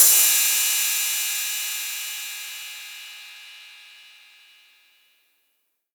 808CY_5_TapeSat_ST.wav